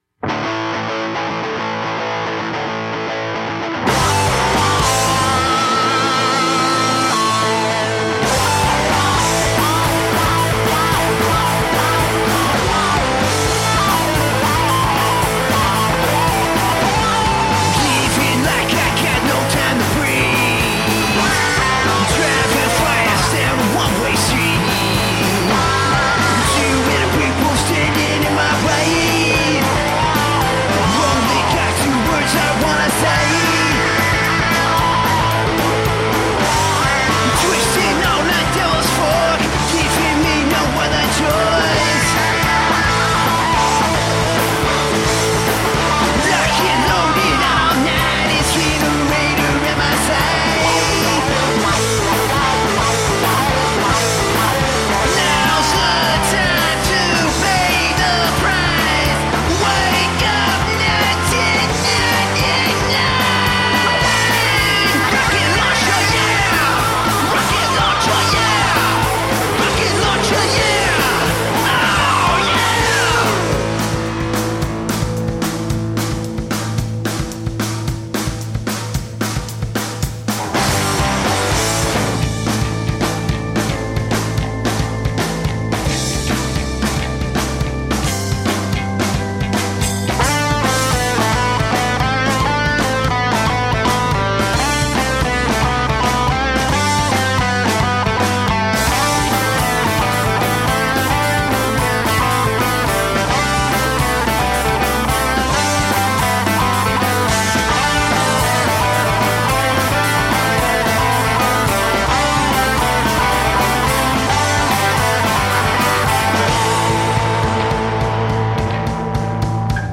High energy rock and roll.
Snotty punk rock reminiscent of the Sex Pistols.
Tagged as: Hard Rock, Metal, Punk, High Energy Rock and Roll